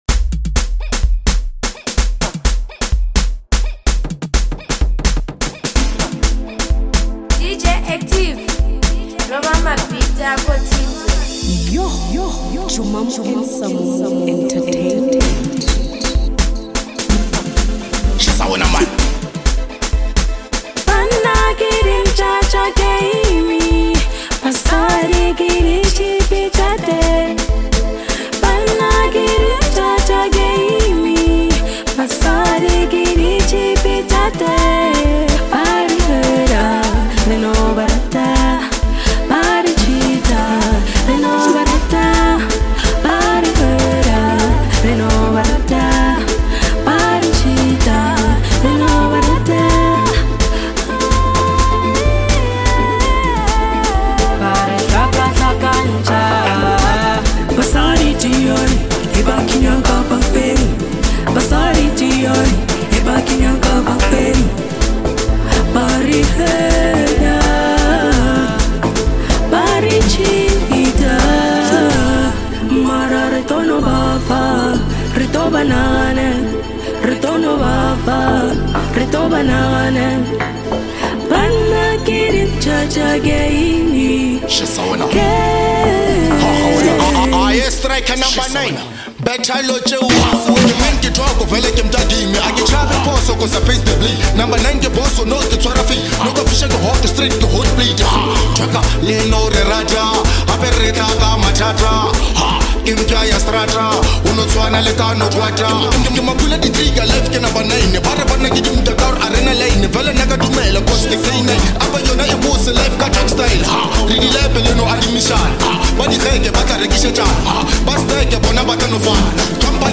a powerful and unapologetic track
raw emotions,intense storytelling